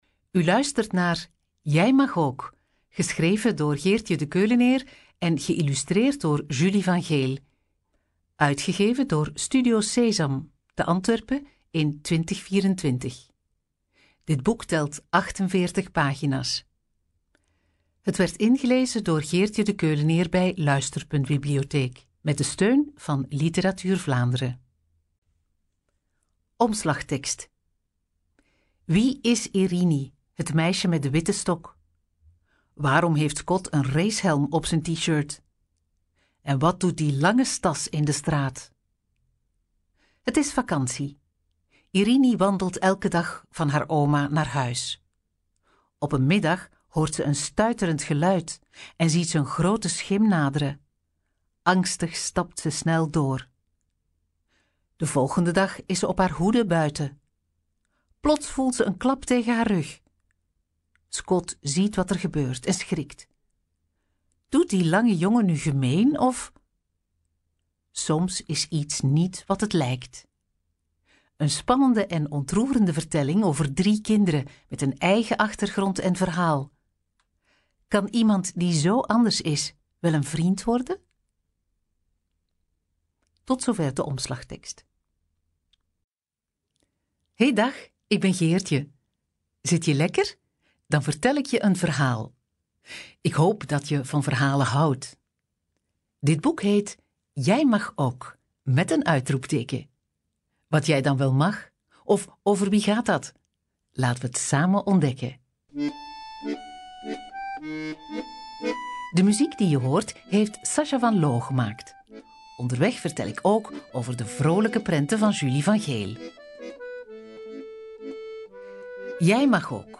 Door de montage met muziek en achtergrondgeluiden is het een levendig en spannend luisterverhaal.
Daarna vertelt ze op een levendige en interactieve manier de drie hoofdstukken. Ze beschrijft ook de illustraties.